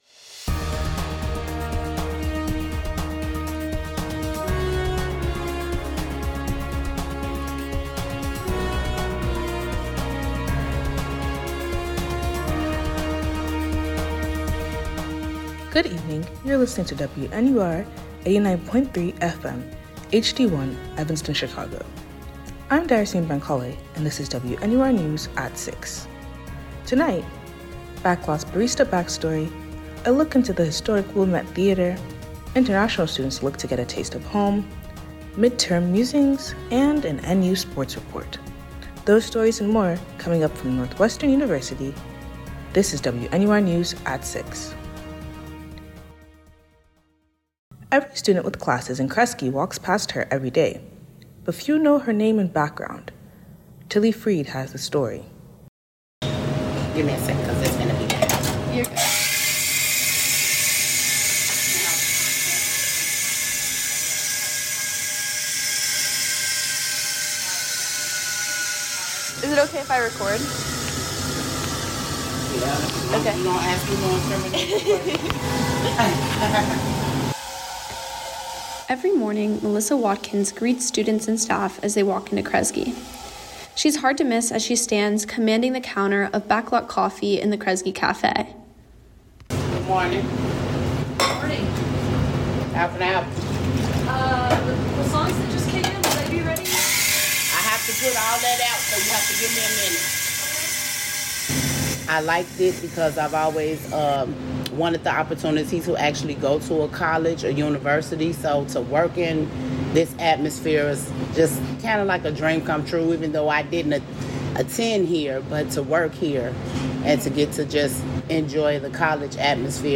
October 22, 2025 Backlot’s barista backstory, a look into the historic Wilmette Theatre, international students look to get a taste of home, midterm Musings, and an NU sports report. WNUR News broadcasts live at 6 pm CST on Mondays, Wednesdays, and Fridays on WNUR 89.3 FM.